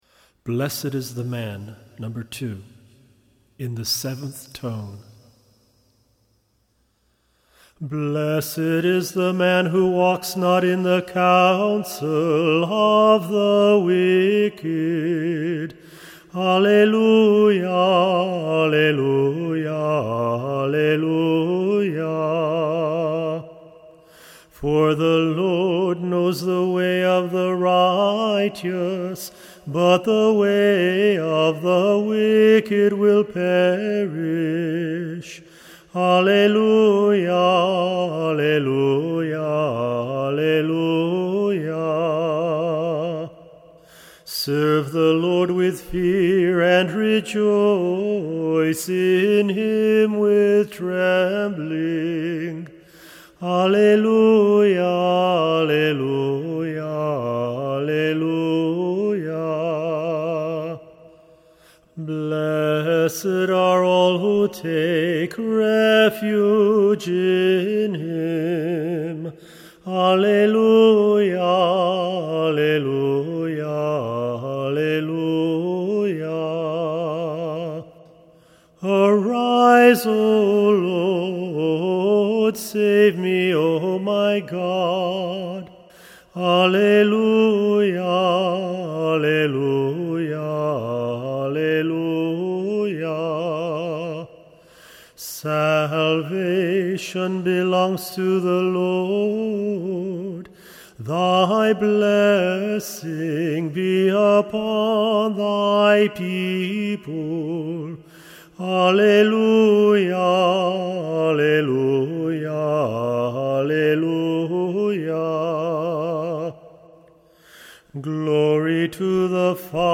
Tone 7 pg.2